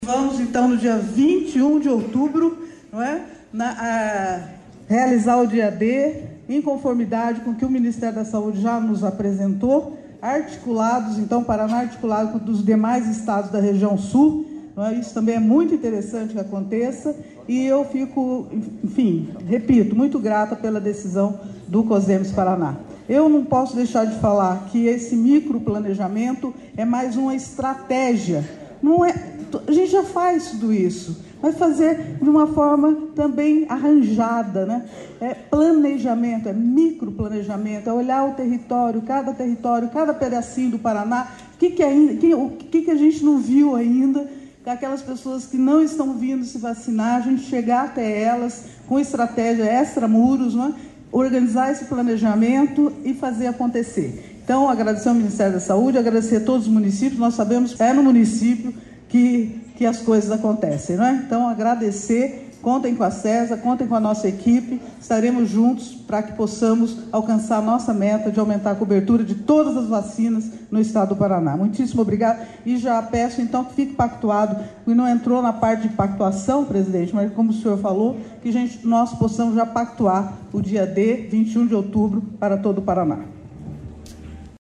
Sonora da diretora de Atenção e Vigilância em Saúde da Sesa, Maria Goretti David Lopes, sobre a definição do Dia D de Multivacinação